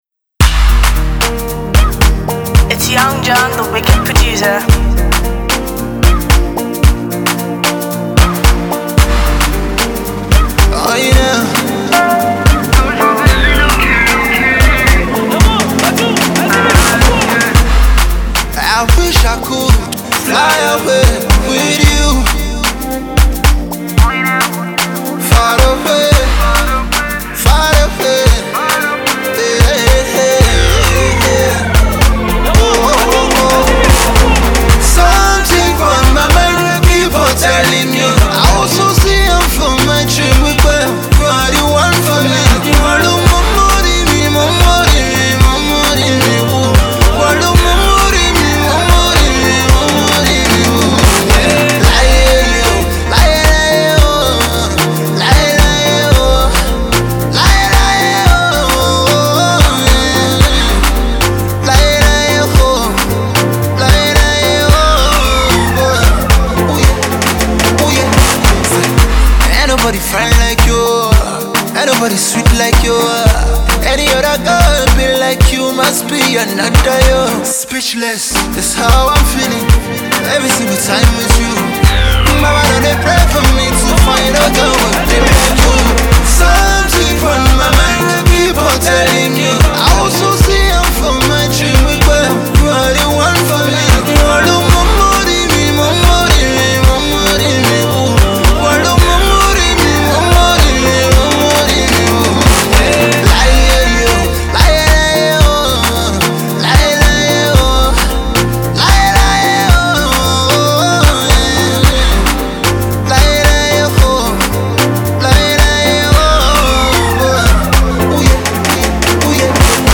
Afro-pop
croons over a lush poppy instrumentation
melody heavy anthemic love ear-worm